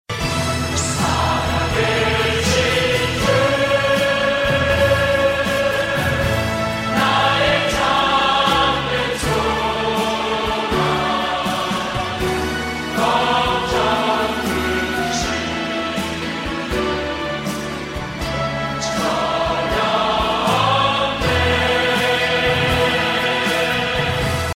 Christian & Gospel RingTones